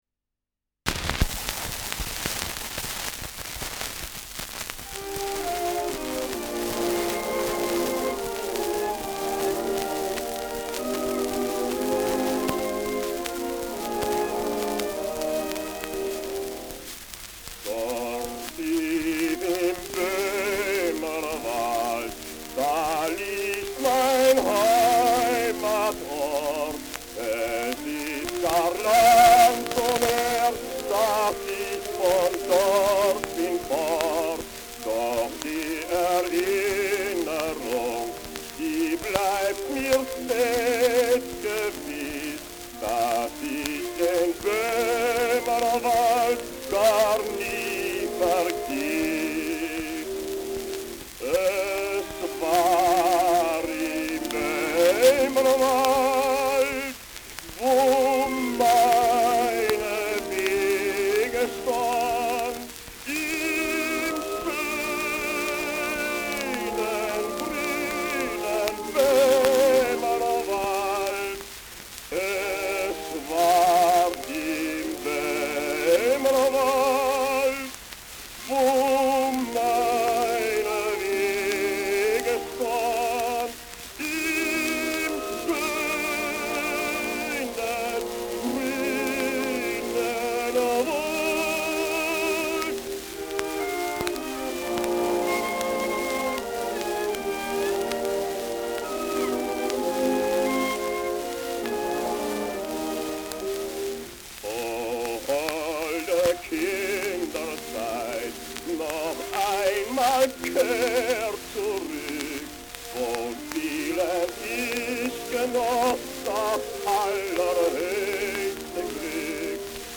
Tief im Böhmerwald : Walzerlied
Schellackplatte
Stark abgespielt : Erhöhtes Grundrauschen : Verzerrt an lauteren Stellen : Durchgehend leichtes Knacken
[unbekanntes Ensemble] (Interpretation)
Etikett: Zonophone Record : International Zonophone Company : German Bass w. Orch. : Tief im Böhmerwald : Walzerlied von Th.
mit Orchesterbegleitung